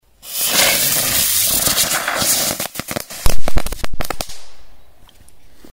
Звуки петард